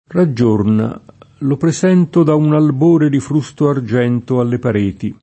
presentire v.; presento [preS$nto] — es.: io ricordo il passato e presento l’avvenire [io rik0rdo il paSS#to e ppreS$nto l avven&re] (Carducci); Raggiorna, lo presento Da un albore di frusto Argento alle pareti [